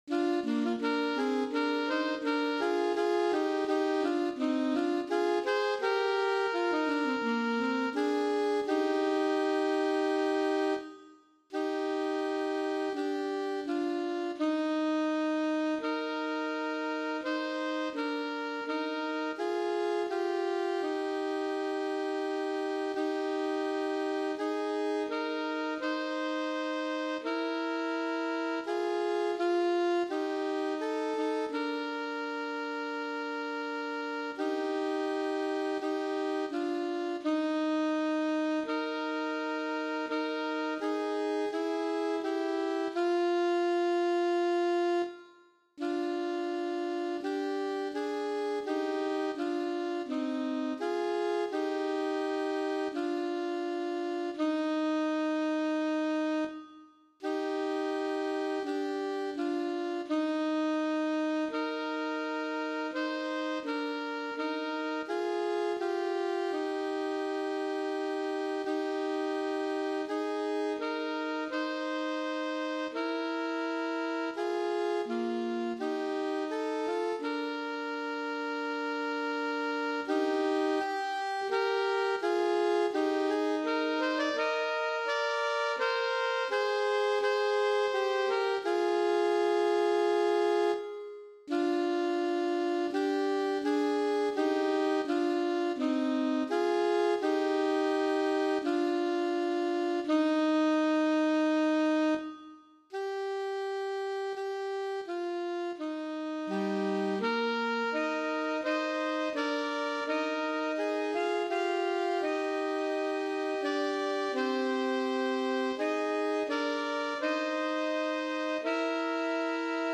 • Easy-Medium